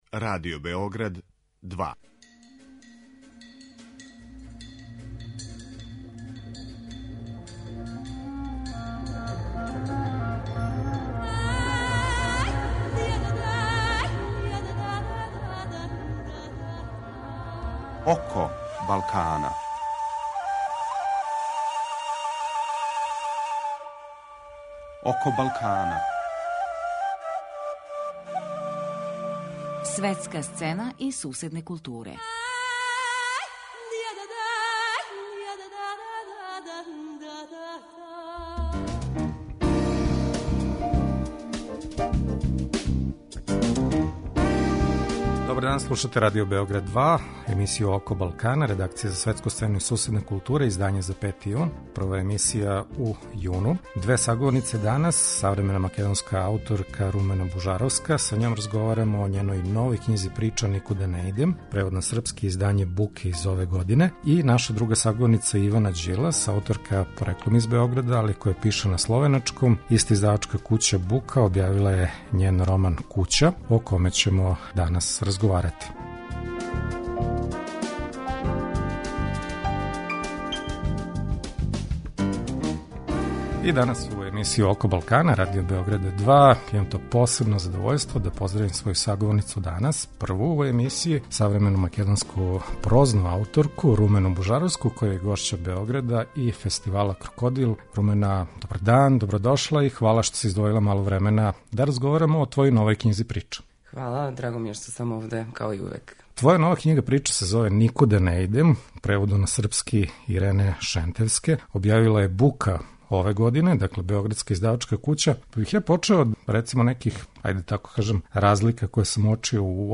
Гошћа емисије је савремена македонска прозаисткиња Румена Бужаровска, гошћа овогодишњег фестивала "Крокодил".